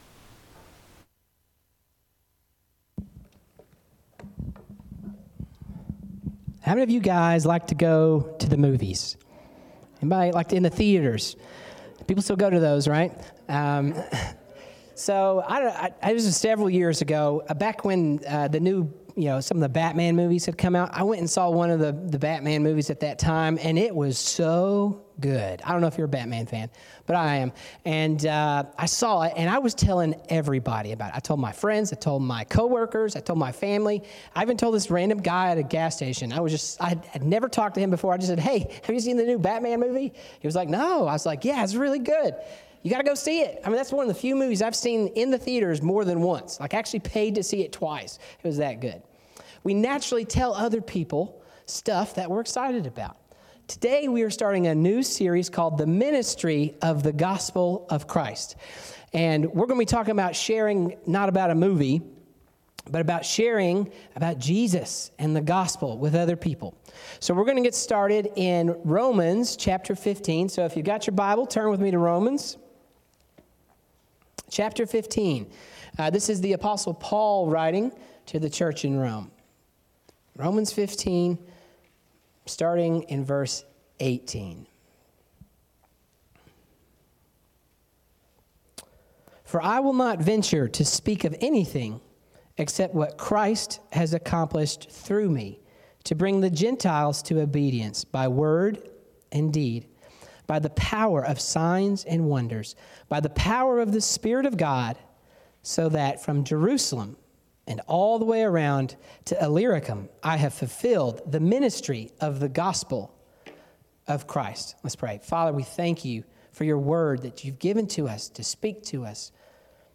Sermons | Robertsville Baptist Church